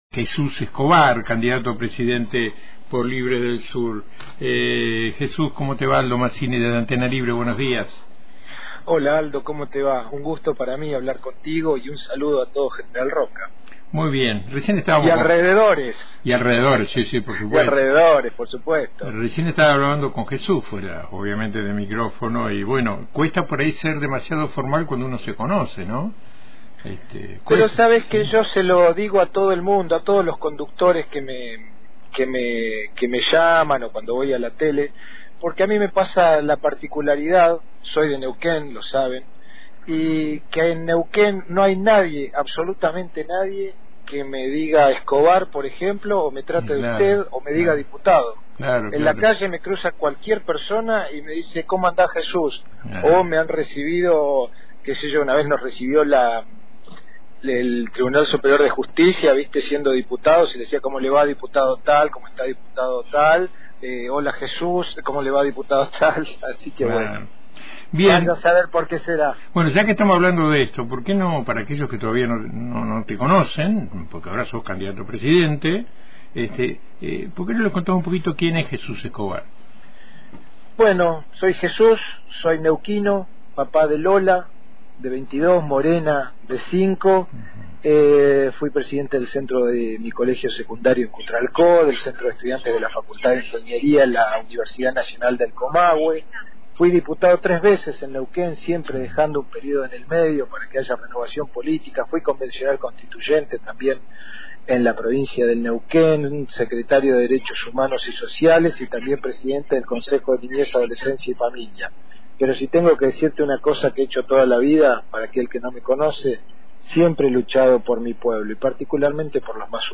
El neuquino pre candidato a presidente por Libres del Sur, Jesús Escobar, dialoga con Antena Libre para presentar su programa político y ofrece su visión sobre el panorama político actual.